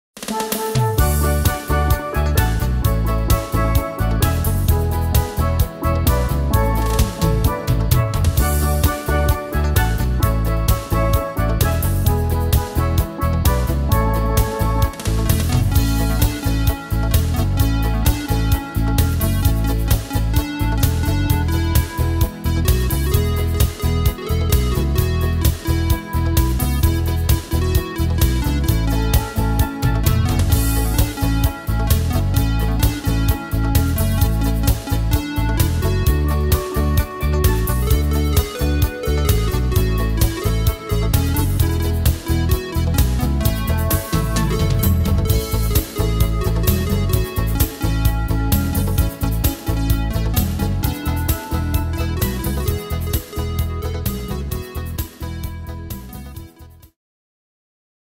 Tempo: 130 / Tonart: A-Dur